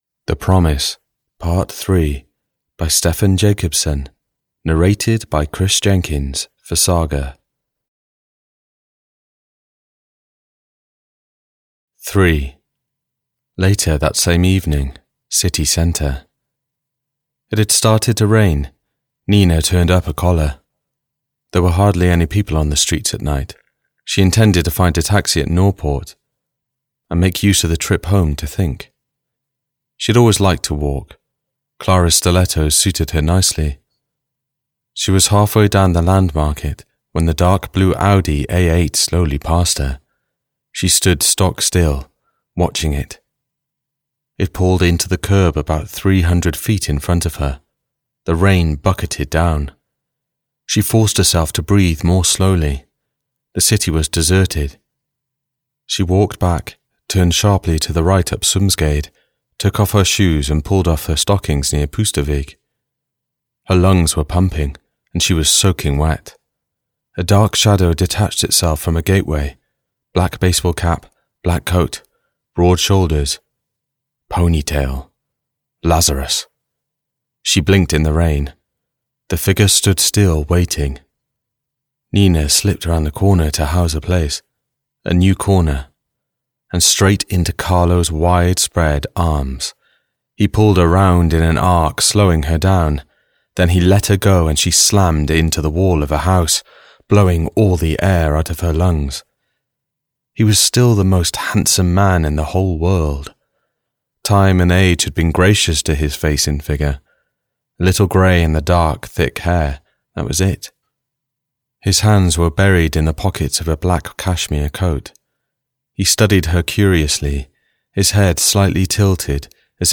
The Promise - Part 3 (EN) audiokniha
Ukázka z knihy